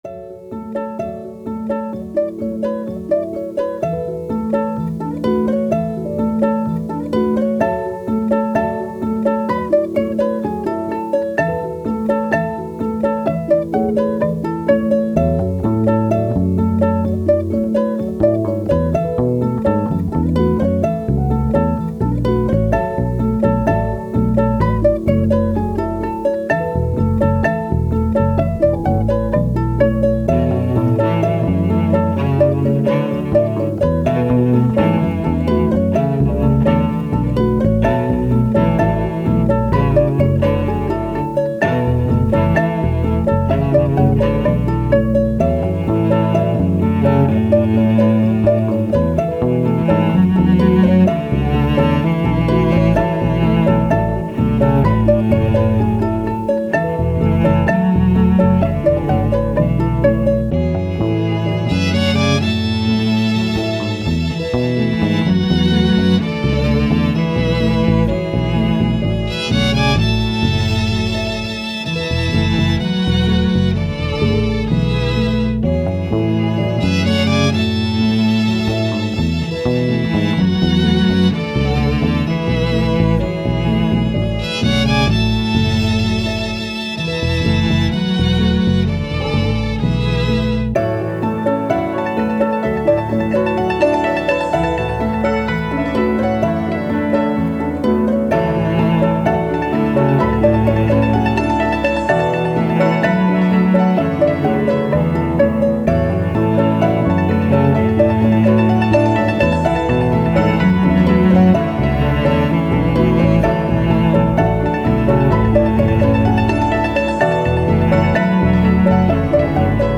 Soundtrack, Ambient, Downtempo, Strings, Thoughtful